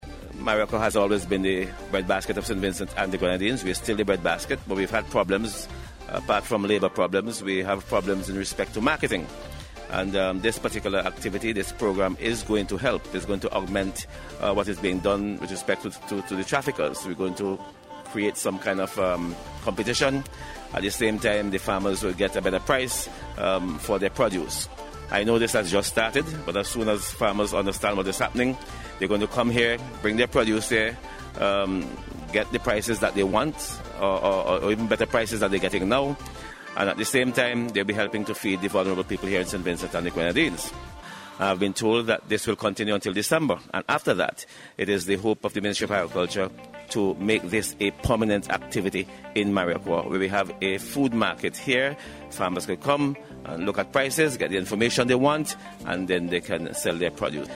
Meanwhile, Minister of Health and Parliamentary Representative for Marriaqua, St Clair Prince says the Palletization Plant will be beneficial to the Farmers.